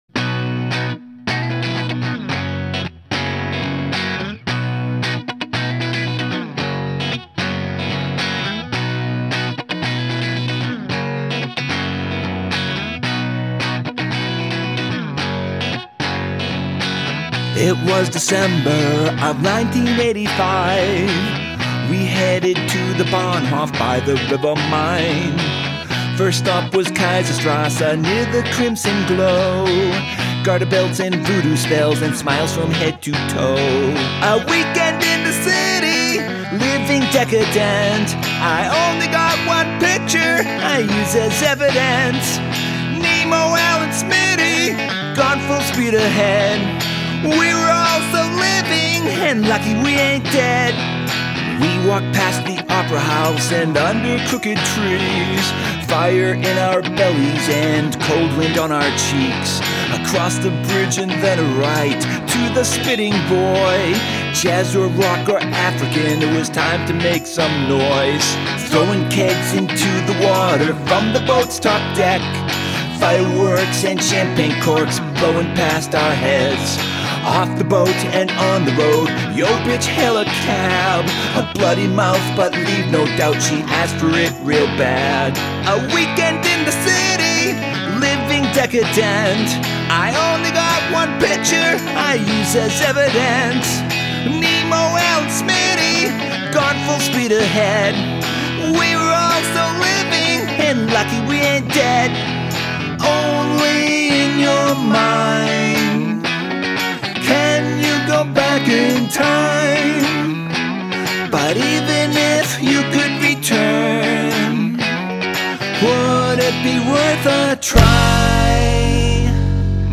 Epic story song
The fretless bass is really tasty throughout.